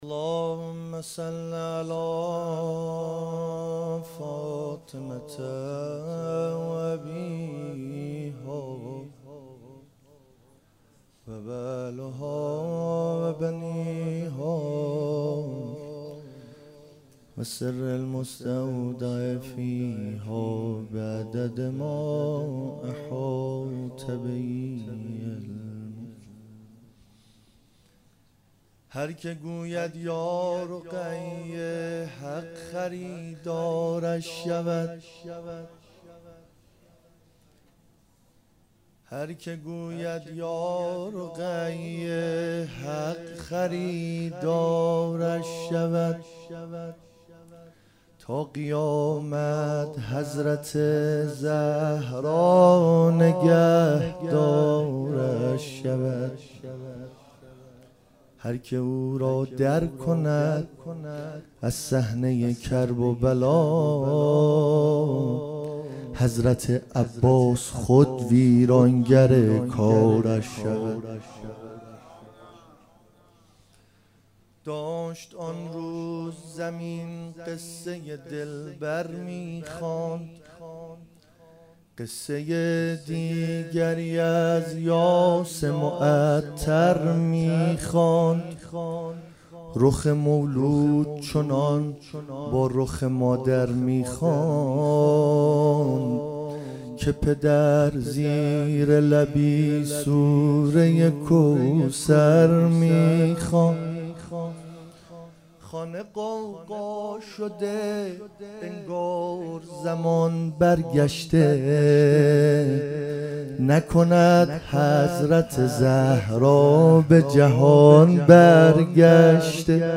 ولادت حضرت رقیه سلام الله علیها97 - مدح خوانی
ولادت حضرت رقیه سلام الله علیها